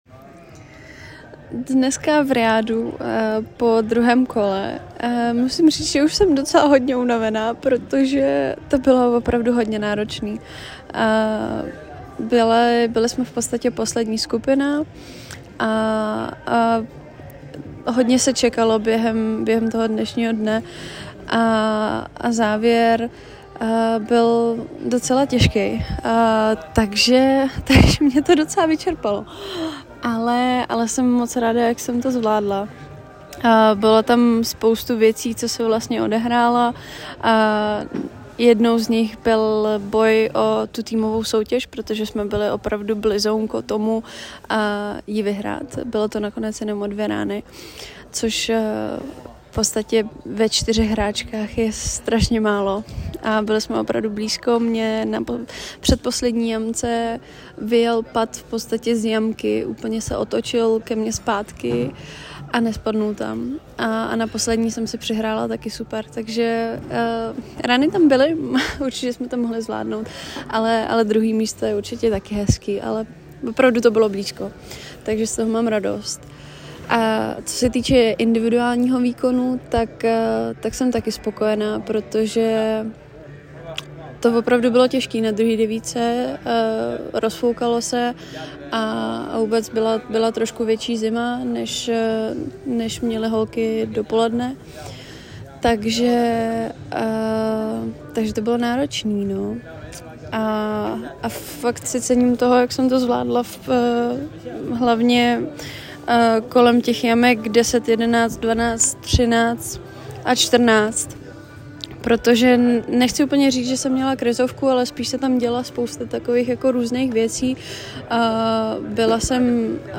Hlasová reakce